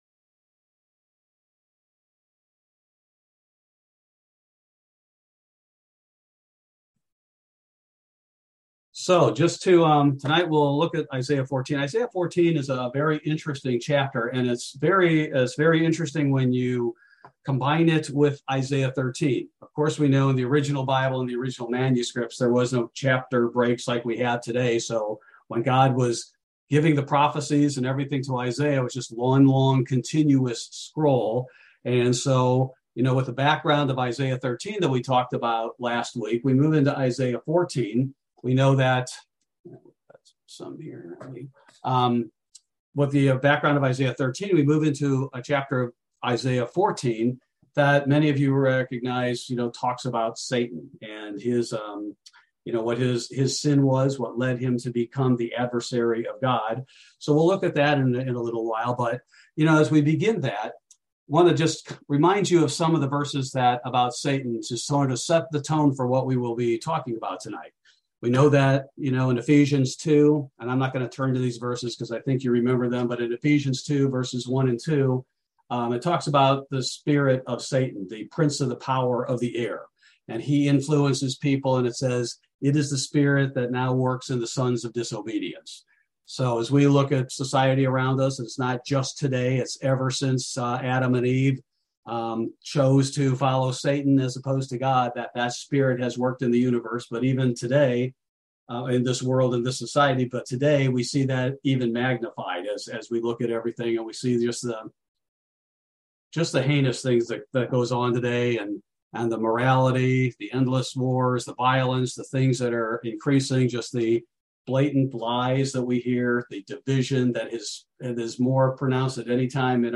Bible Study: November 2, 2022